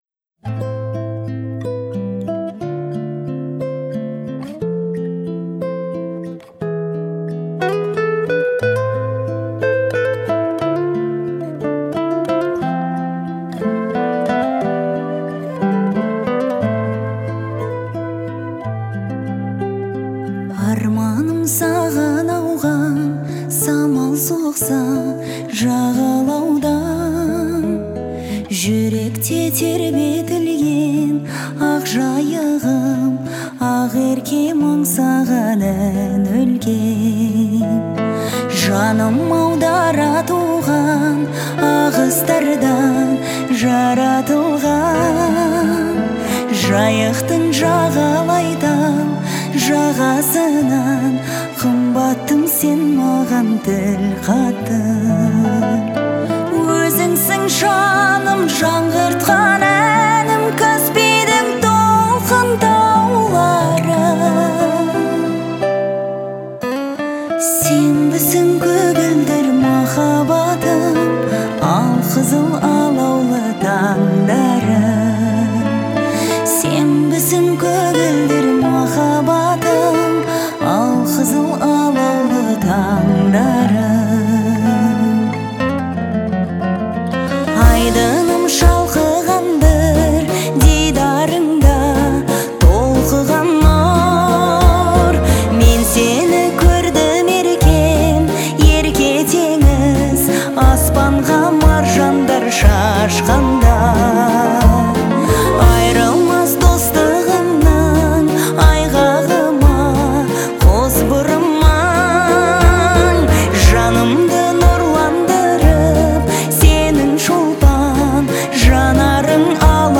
это трогательная песня в жанре казахской народной музыки
используя выразительные интонации и мягкий вокал.